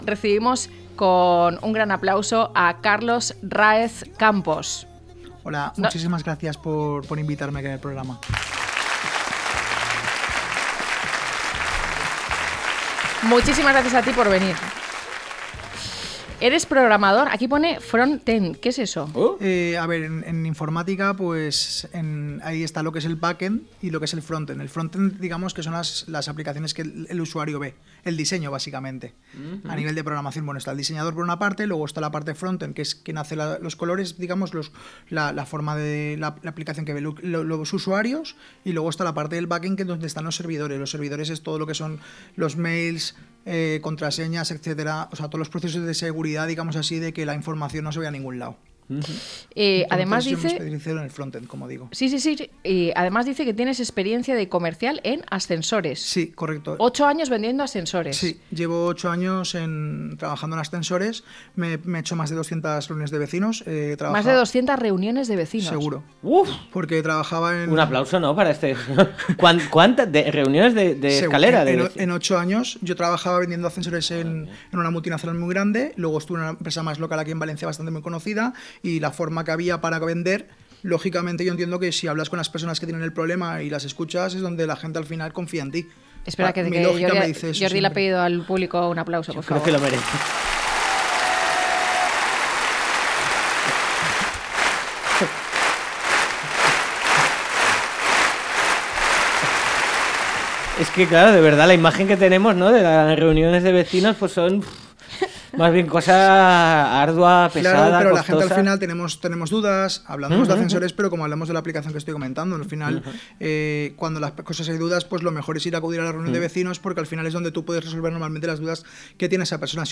0306-LTCM-ENTREVISTA-1.mp3